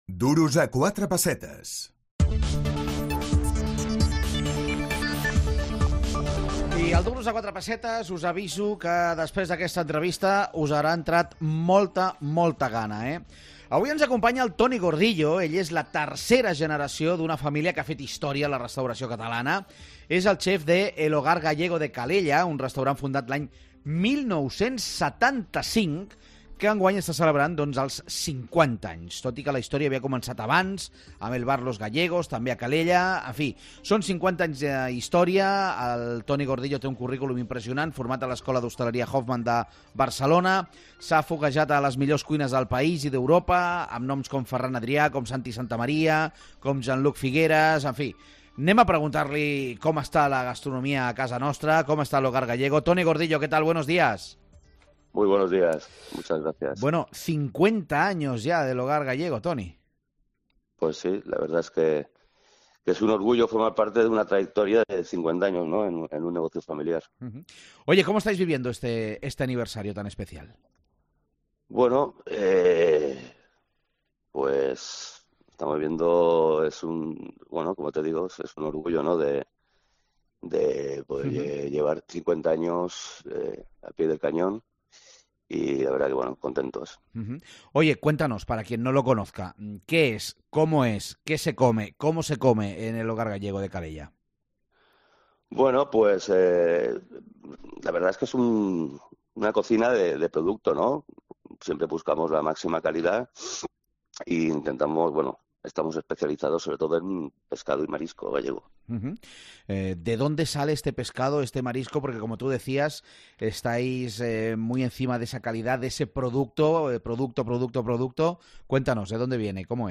Indicatiu del programa, entrevista
Divulgació